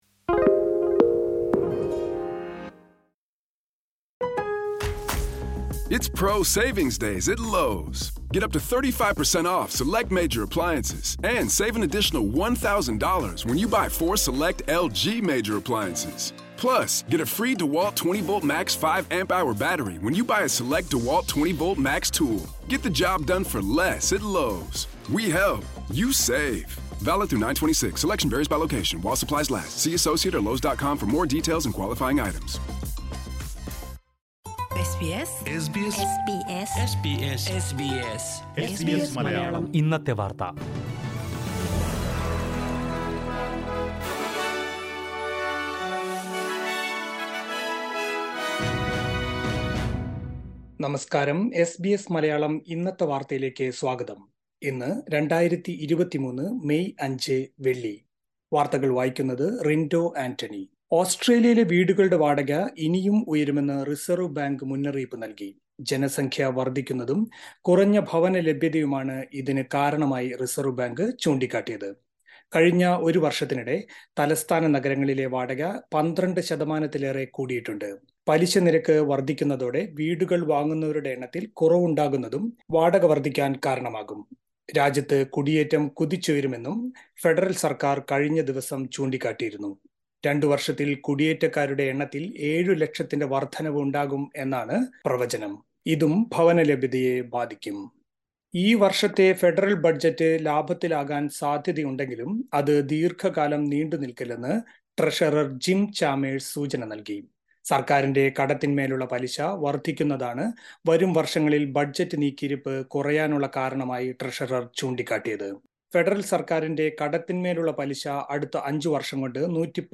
2023 മെയ് അഞ്ച് വെള്ളിയാഴ്ചയിലെ പ്രധാന വാർത്തകൾ കേൾക്കാം